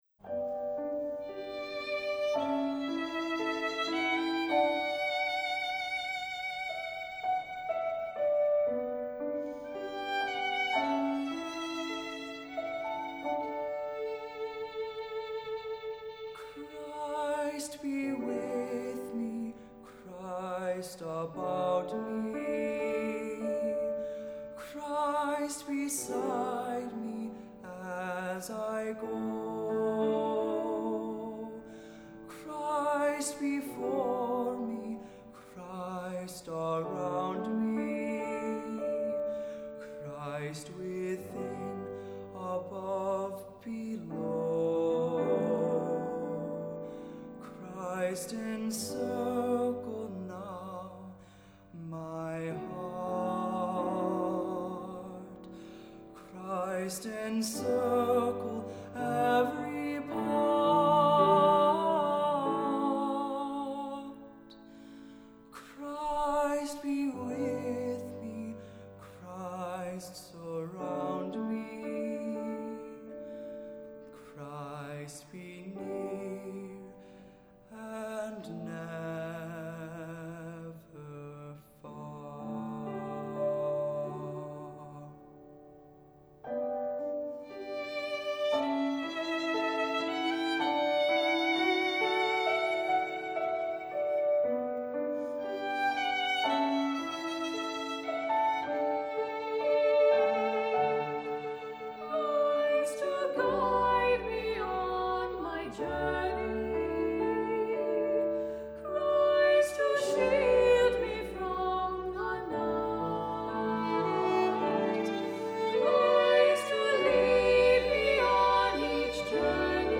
Accompaniment:      Keyboard, C Instrument
Music Category:      Christian
Solo(s)